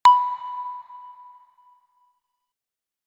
Radar.ogg